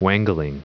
Prononciation du mot wangling en anglais (fichier audio)
Prononciation du mot : wangling